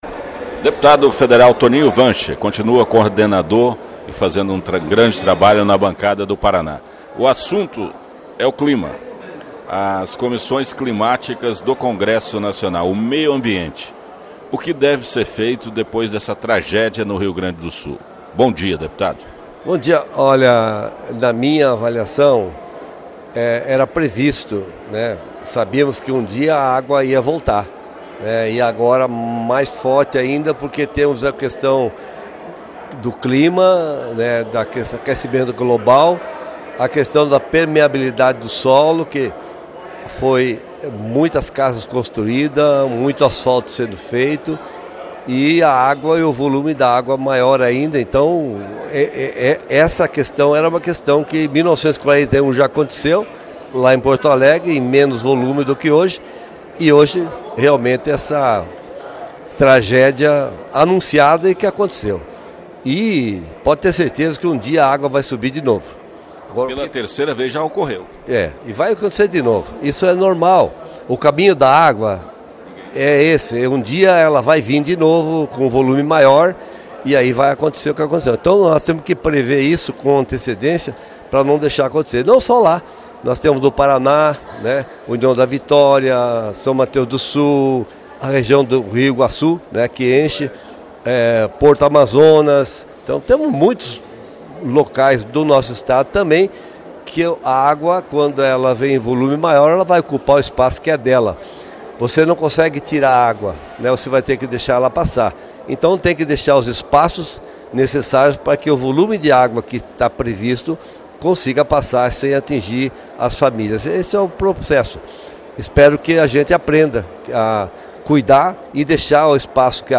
toninho voz